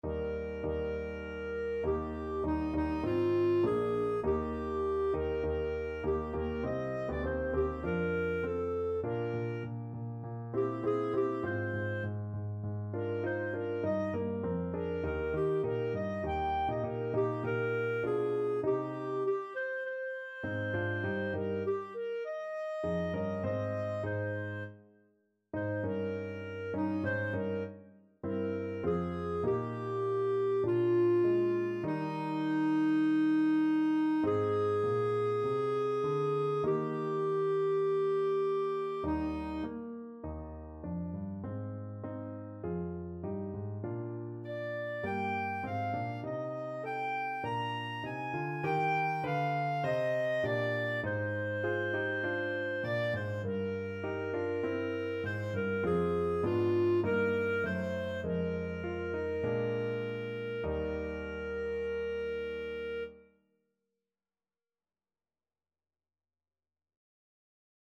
Classical Lutkin, Peter C. The Lord Bless You and Keep You (Benediction) Clarinet version
Clarinet
Eb major (Sounding Pitch) F major (Clarinet in Bb) (View more Eb major Music for Clarinet )
4/4 (View more 4/4 Music)
Classical (View more Classical Clarinet Music)